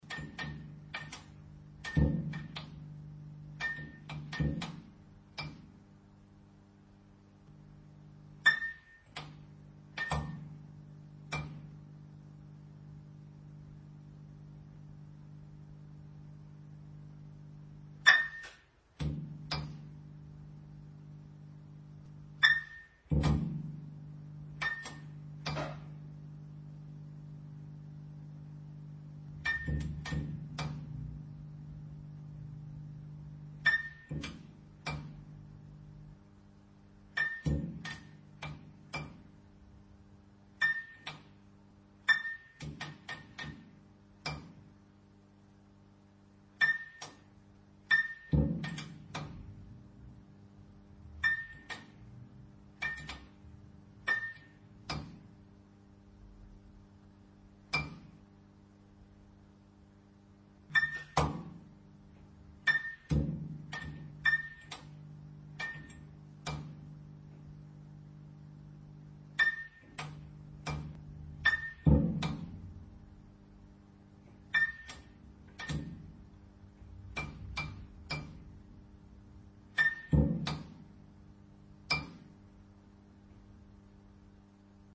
Звук мигания неисправной лампы дневного света
• Категория: Лампы и светильники
• Качество: Высокое
На этой странице вы можете прослушать звук звук мигания неисправной лампы дневного света.